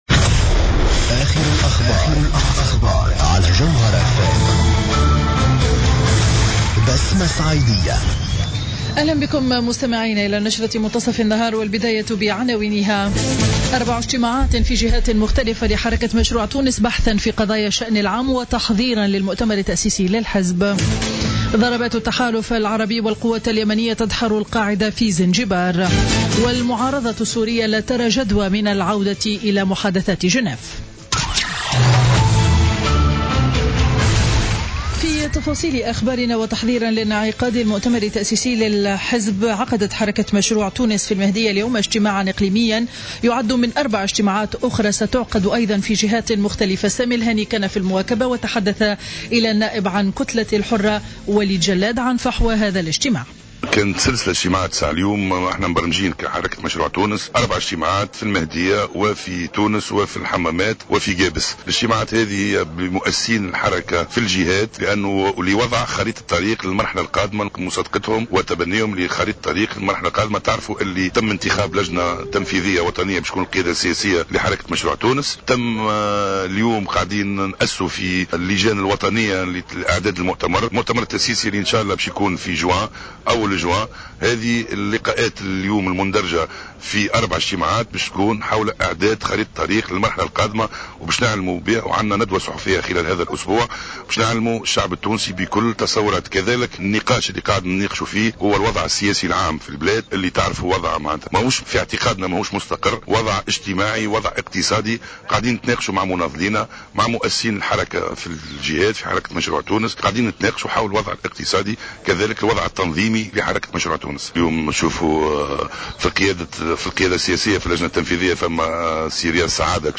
نشرة أخبار منتصف النهار ليوم الأحد 24 أفريل 2016